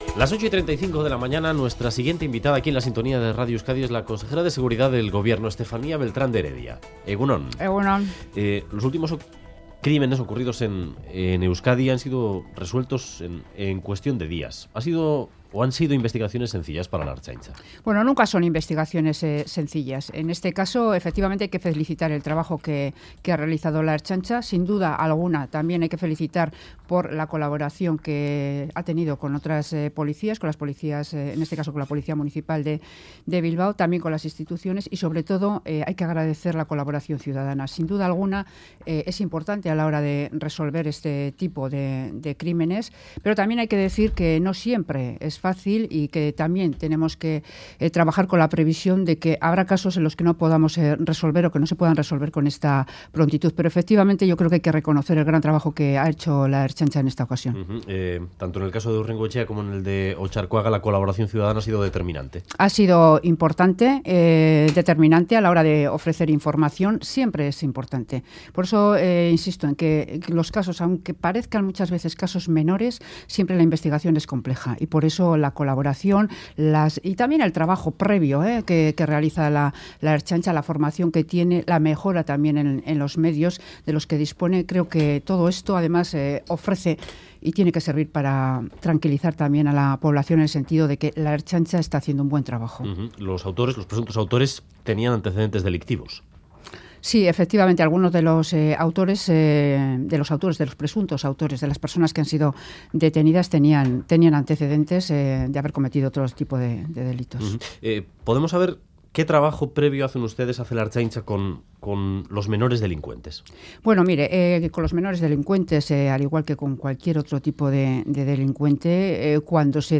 Audio: Beltrán de Heredia habla sobre los crímenes de Bilbao y la seguridad